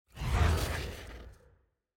sfx-jfe-ui-roomselect-appear.ogg